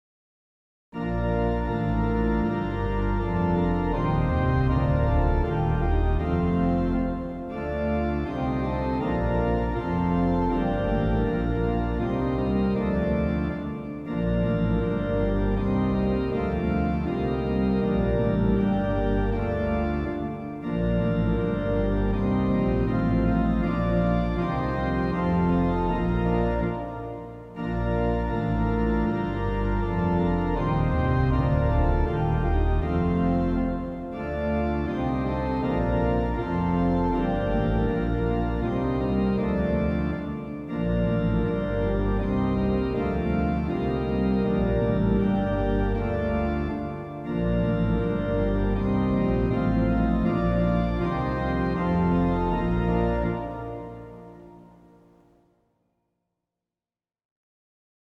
(nápěvy upravené)
Polsko 16. stol.
mp3-nácvik